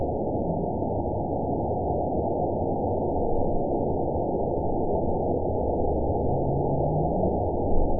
event 911728 date 03/07/22 time 13:14:01 GMT (3 years, 2 months ago) score 8.60 location TSS-AB06 detected by nrw target species NRW annotations +NRW Spectrogram: Frequency (kHz) vs. Time (s) audio not available .wav